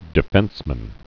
(dĭ-fĕnsmən, -măn)